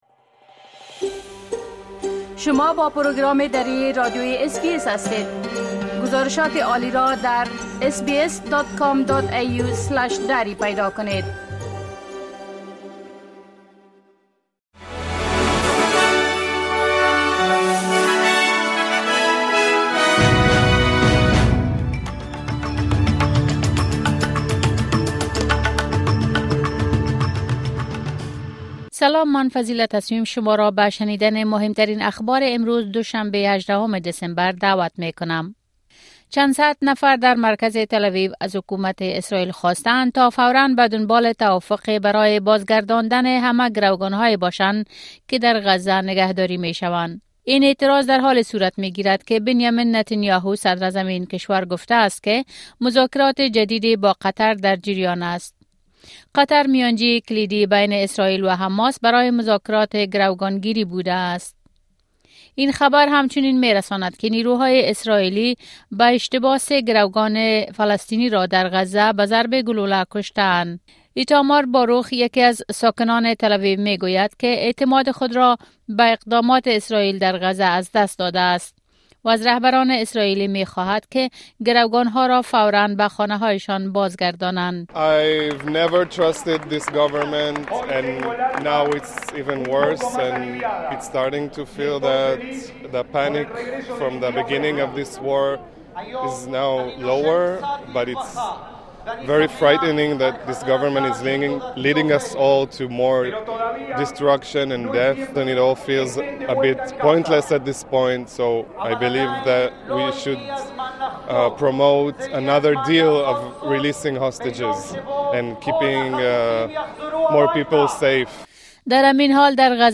خلاصه اخبار روز از اس‌بی‌اس دری